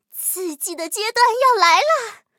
M10狼獾夜战语音.OGG